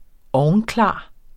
Udtale [ ˈɒwnˌklɑˀ ]